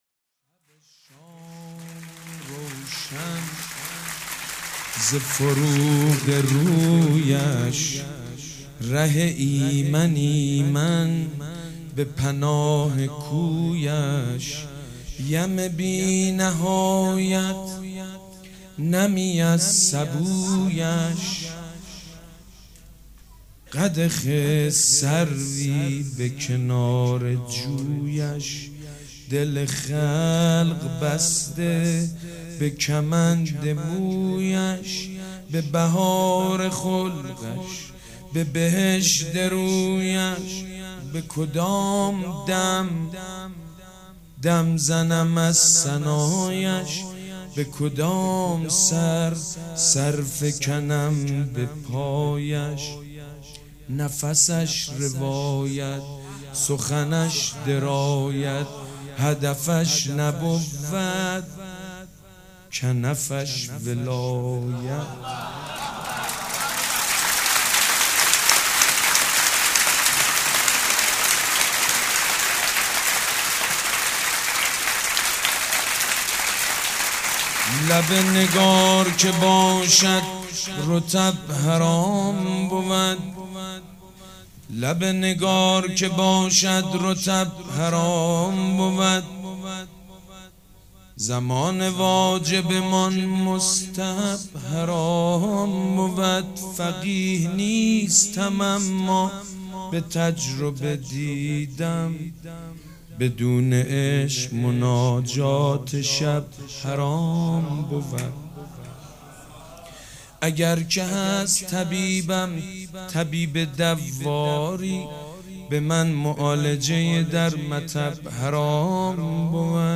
شعر خوانی مداح حاج سید مجید بنی فاطمه